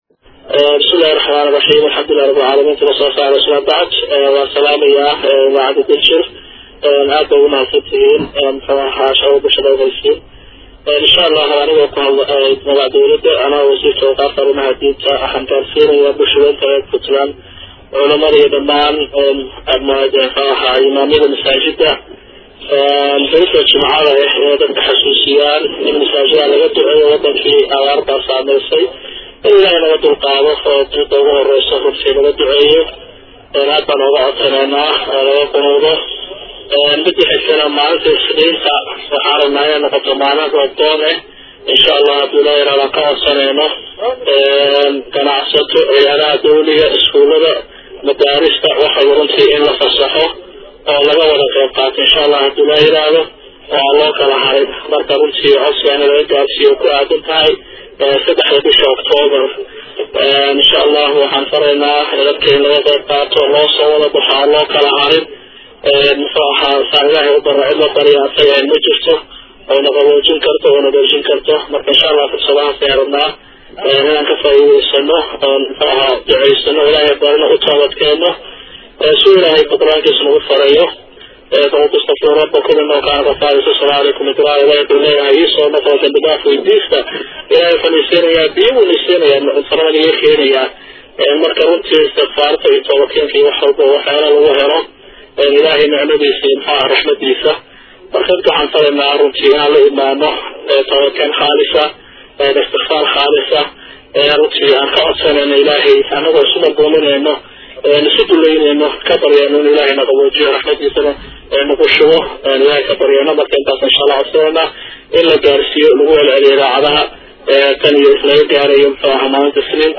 Wasiirka wasaaradda diinta iyo awqaafta oo saxaafadda la hadlay ayaa waxaa uu sheegay in loo baahan yahay Roobdoon guud, oo laga qabto dhamaan dhulka Puntland iyo Soomaaliya.